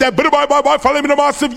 Index of /m8-backup/M8/Samples/breaks/breakcore/evenmorebreaks/v0x